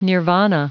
Prononciation du mot nirvana en anglais (fichier audio)
Prononciation du mot : nirvana